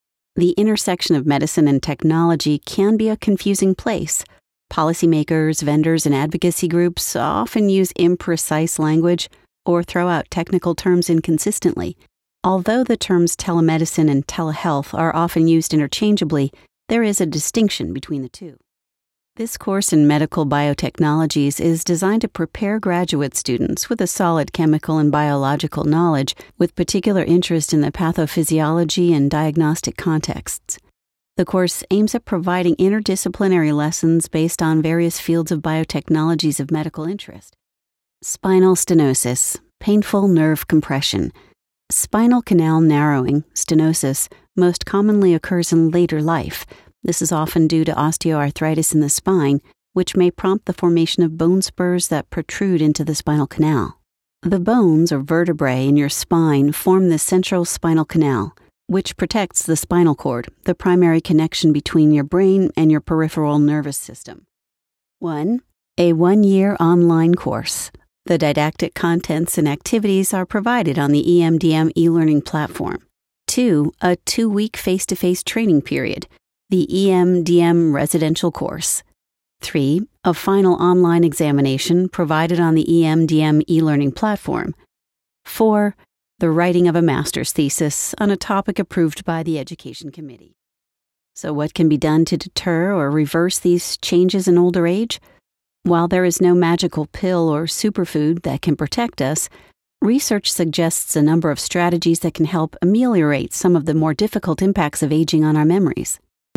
Female Voice Over, Dan Wachs Talent Agency.
Natural, Conversational, Best Friend, Employer.
eLearning